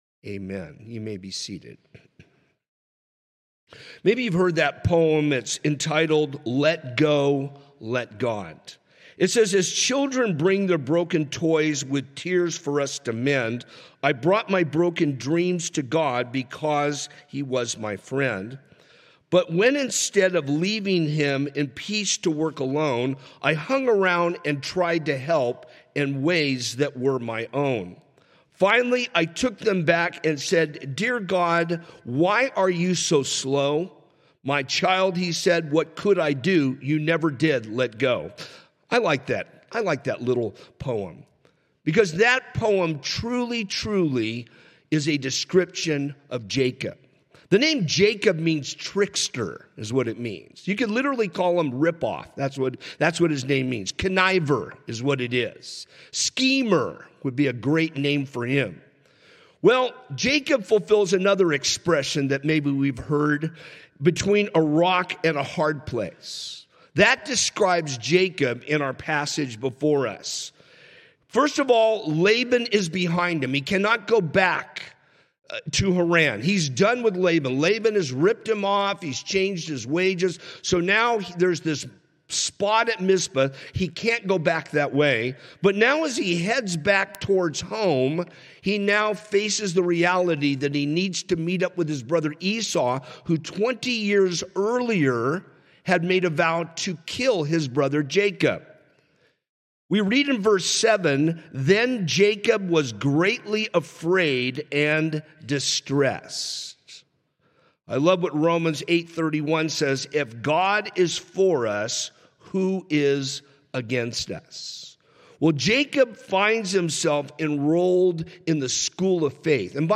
From Series: "Sunday Morning - 10:30"